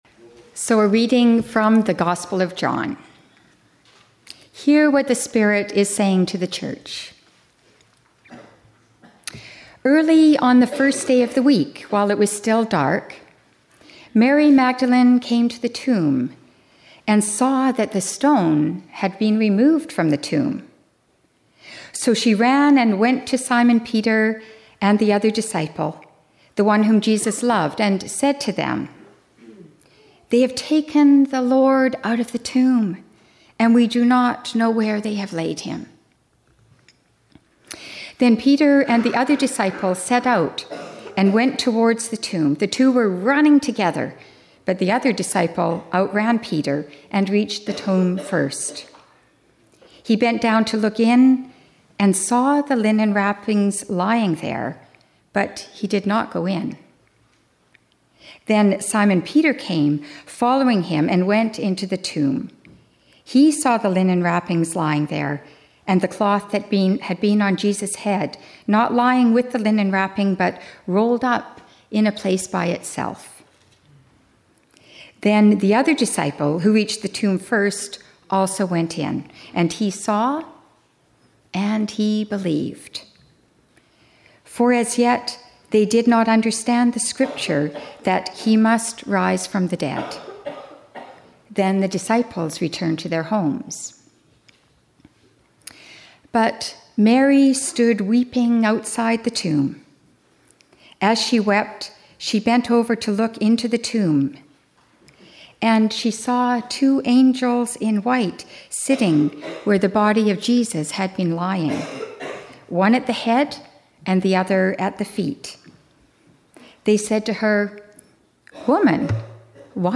Sermons | James Bay United Church